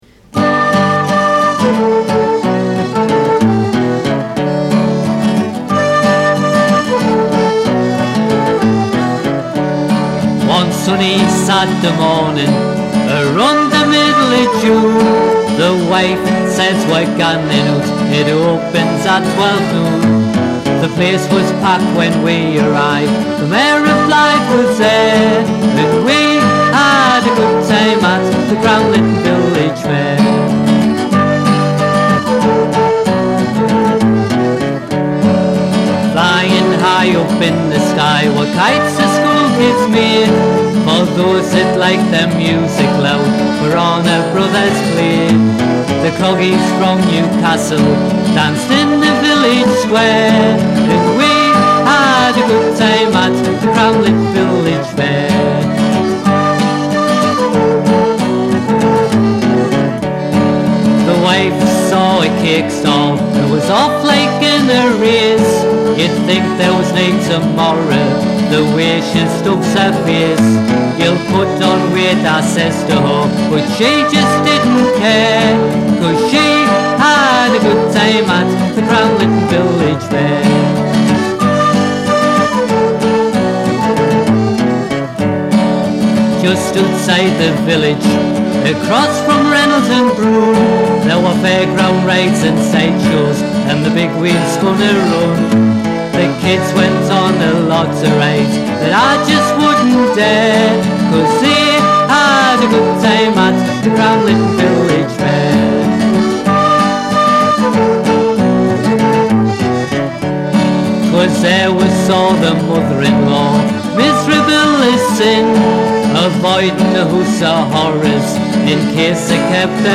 Vocals & Guitar
Recorder
Flute
Piano Accordion.